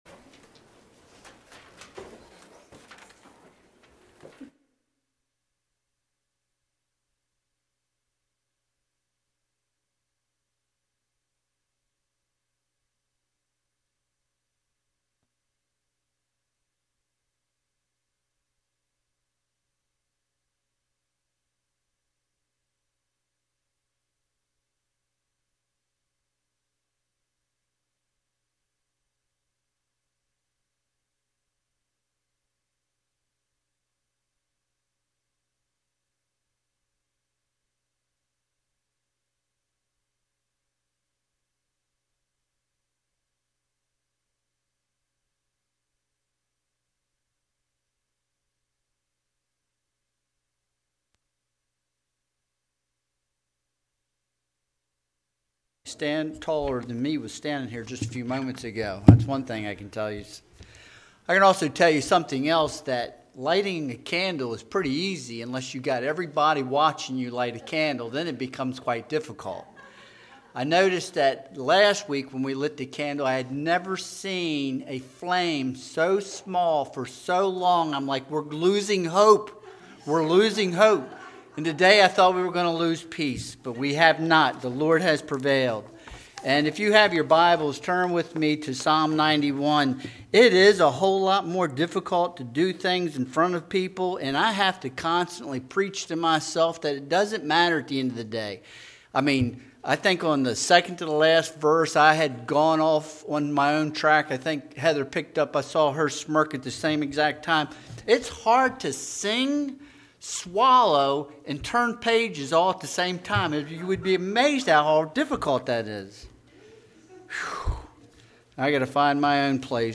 Psalm 91 Service Type: Sunday Worship Service Download Files Bulletin Topics